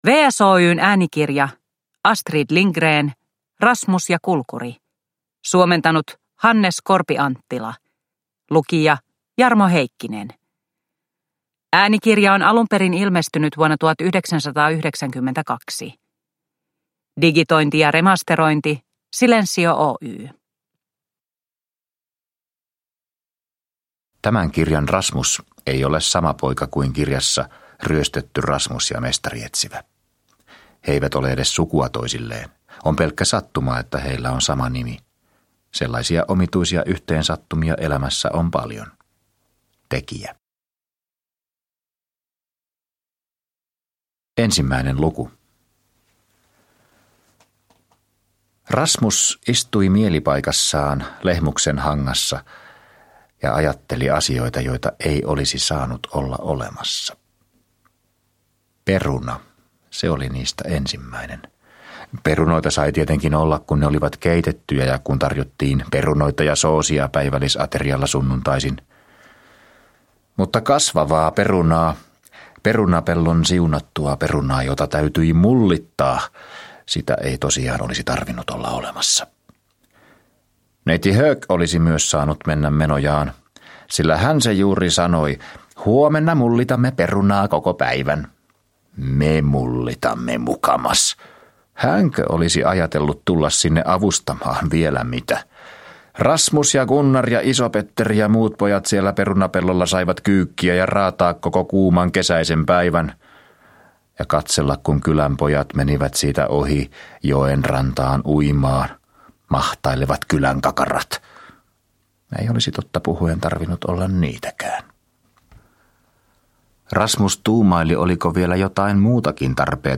Rasmus ja kulkuri – Ljudbok – Laddas ner